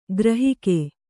♪ grahike